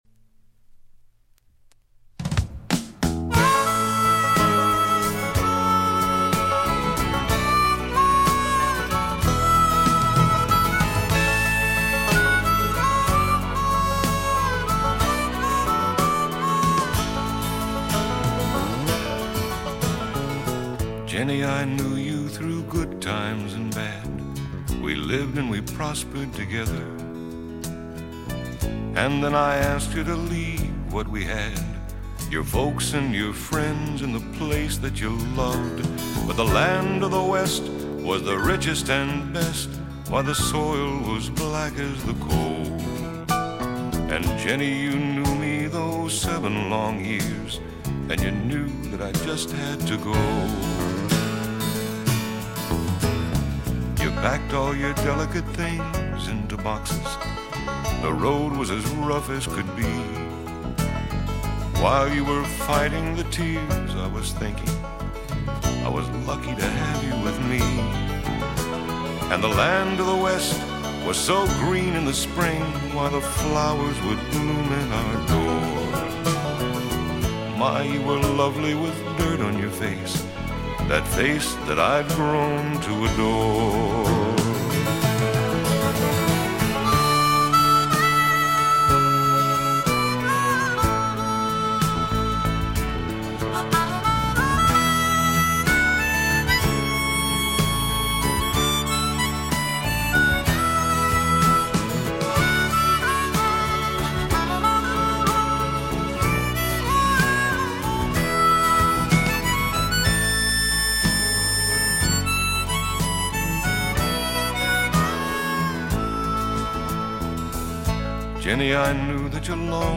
drums
piano
bass guitar
harmonica
banjo
strings
vocals
Folk music--Iowa